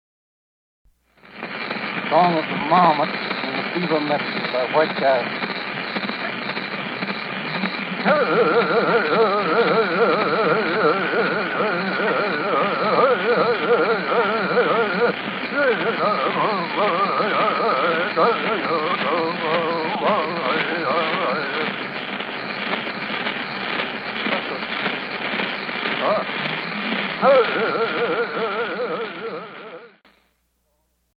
Beaver Medicine Song
recorded by George Bird Grinnell at Piegan Agency, Montana, October 17, 1897.